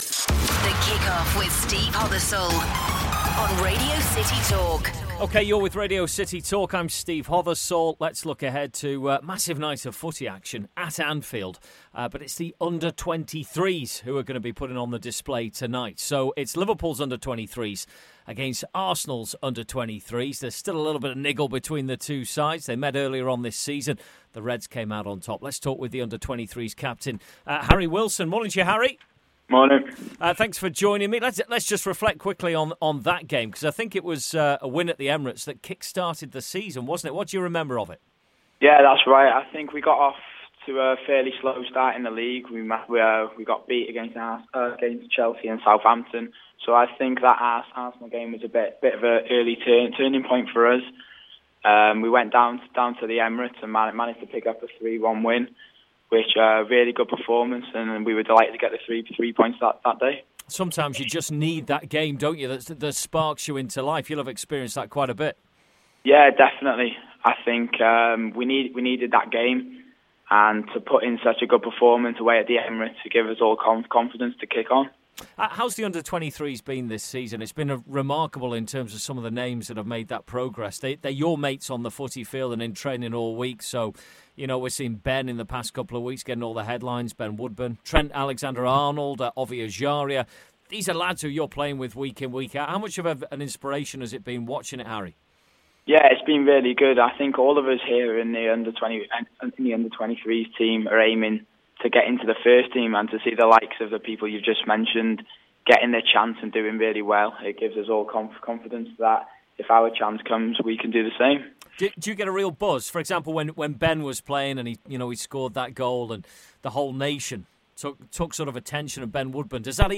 Liverpool's under-23 captain Harry Wilson speaks